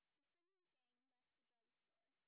sp18_white_snr20.wav